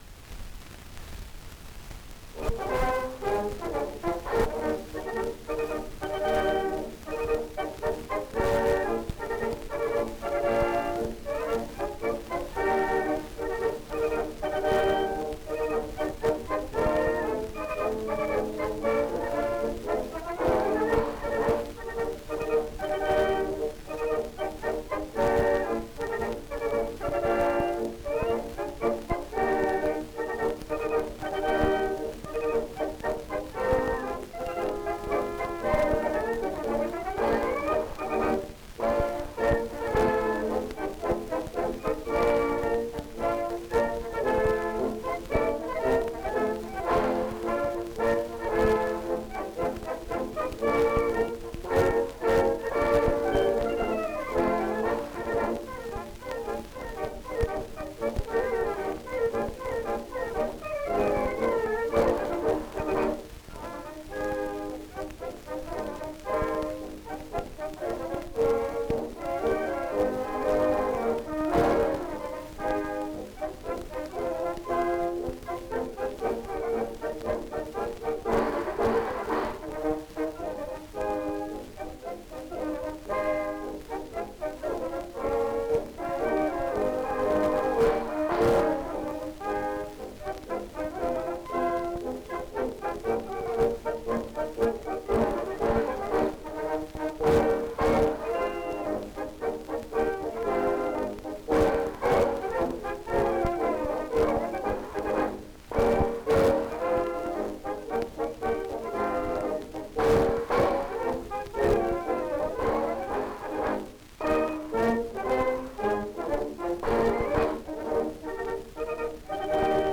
Genre(s) March music
Content Military march
Performer(s) Anker-Orchester (Anker orchestra)
Vocal range Instrumental
Place of recording Berlin (German Reich)